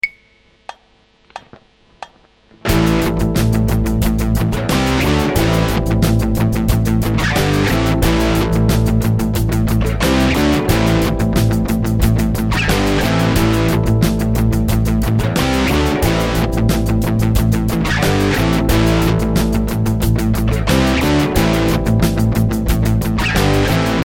The groove used here is based around the drums playing quick 16th notes on the hi-hat.
The next idea is very similar to the previous one but with the addition of power chords at the start of the bar and for the C D and G chords. The power chords help to break up the 16th note rhythm.